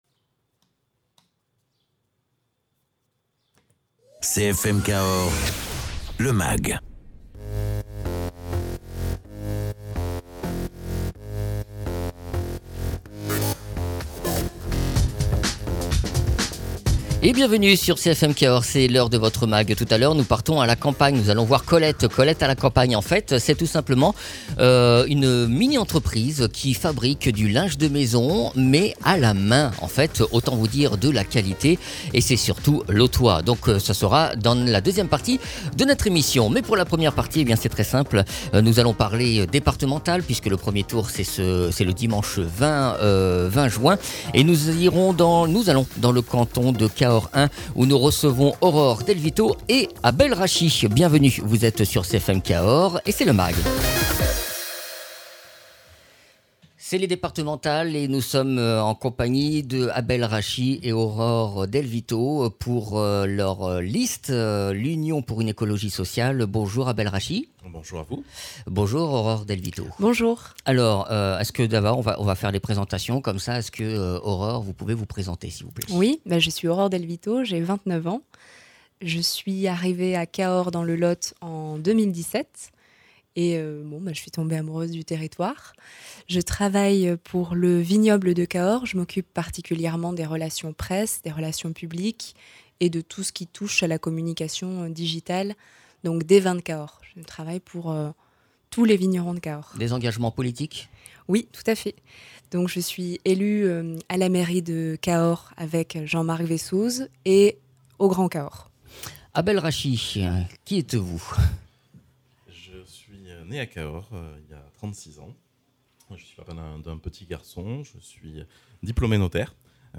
Interviews des candidats aux élections départementales dans le canton de Cahors 1. Egalement dans ce mag, une start up lotoise, Colette à la campagne, propose du linge de maison fait main et responsable.